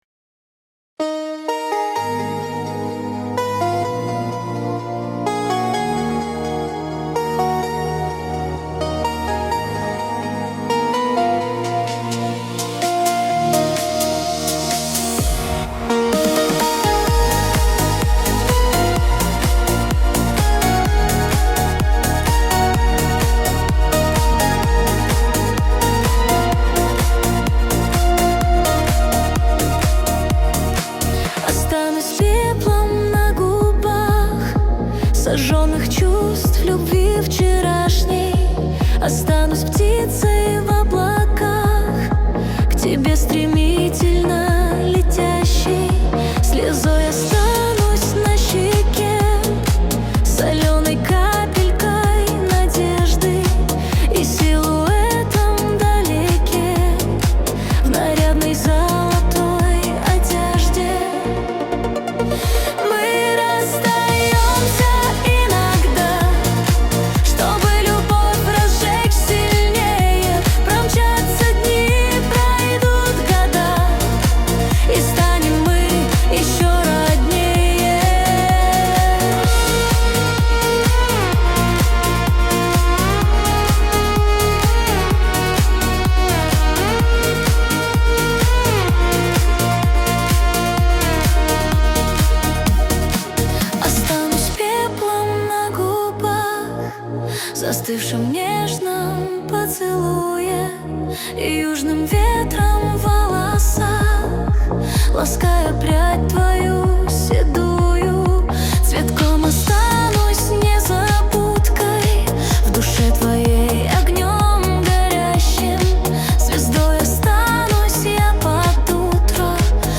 нейросеть